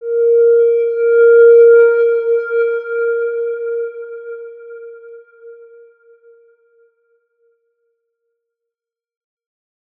X_Windwistle-A#3-mf.wav